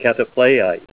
Help on Name Pronunciation: Name Pronunciation: Catapleiite + Pronunciation